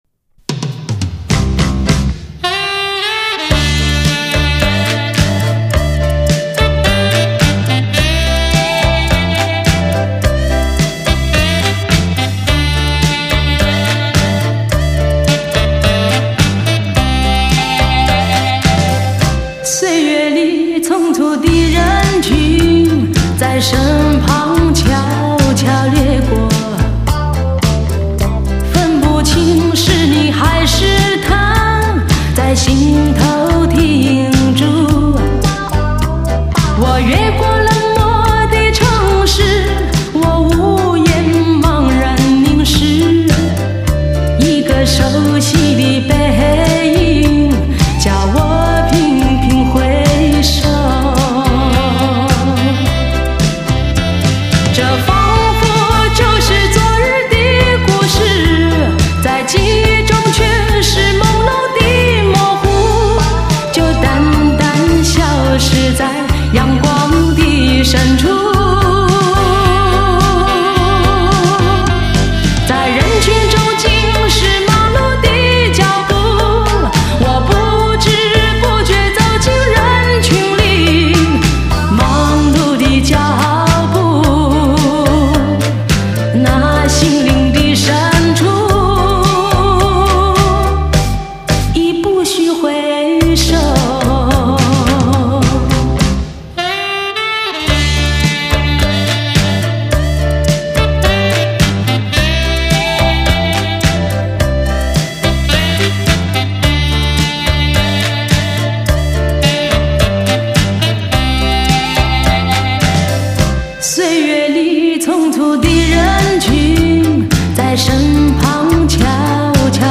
音质一流